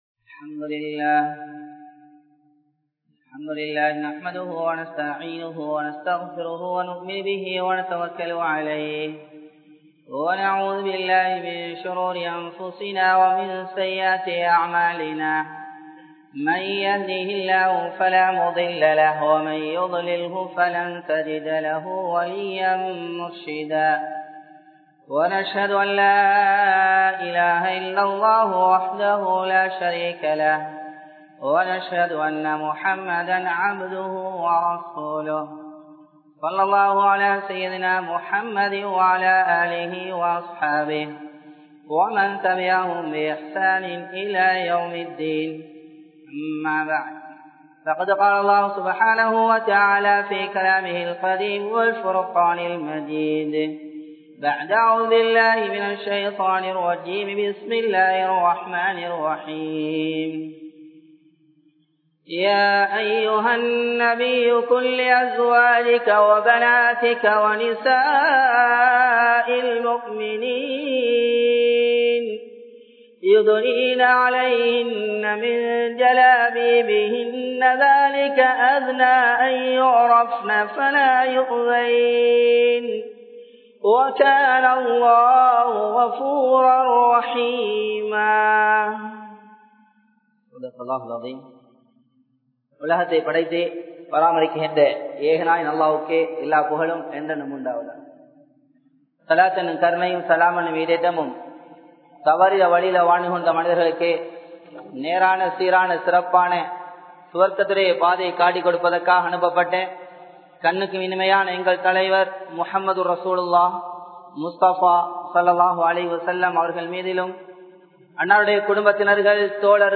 Nirvaanamaana Aadaihal | Audio Bayans | All Ceylon Muslim Youth Community | Addalaichenai